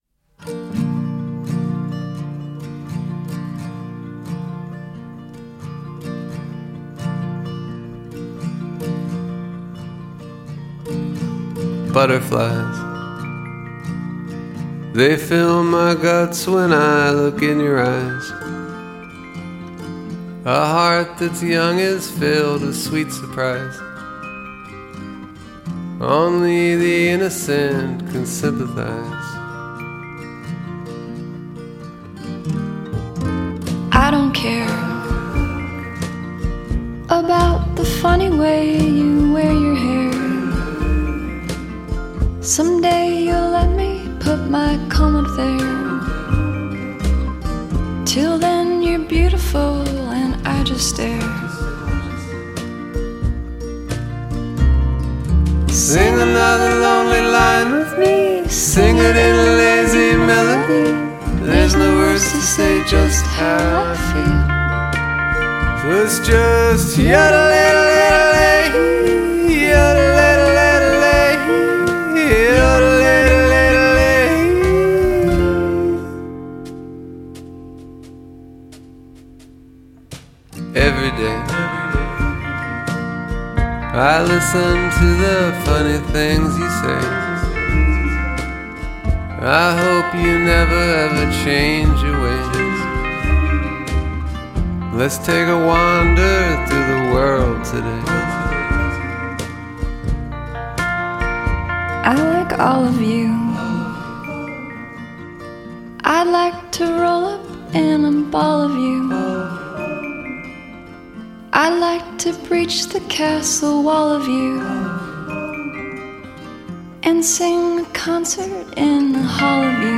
is a duet